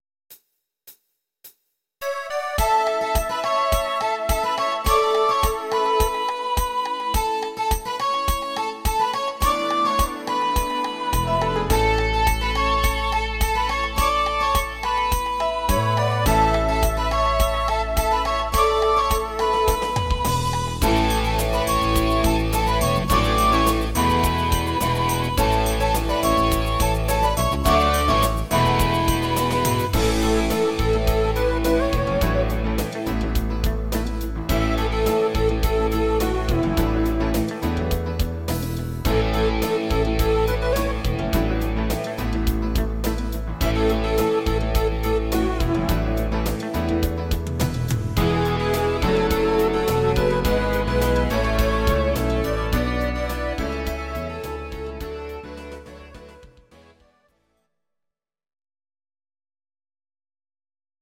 These are MP3 versions of our MIDI file catalogue.
Please note: no vocals and no karaoke included.
Your-Mix: Rock (2958)